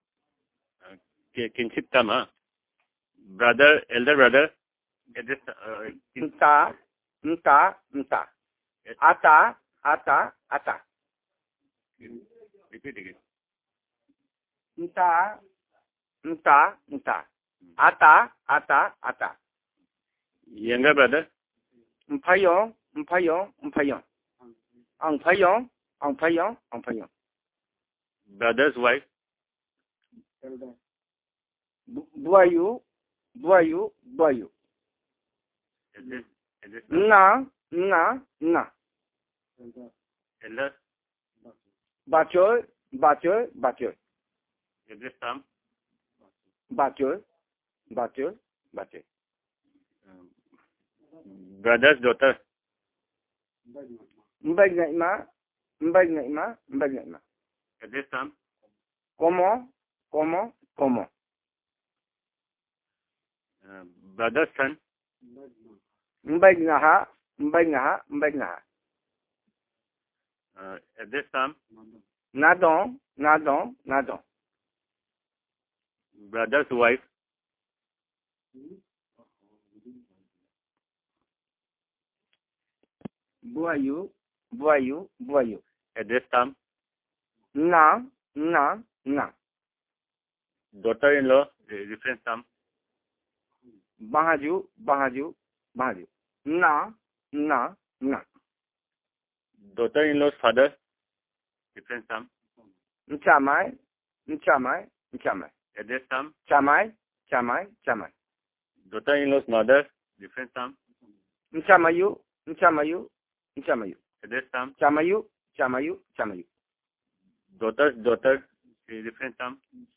Elicitation of words about kinship terms and endearments.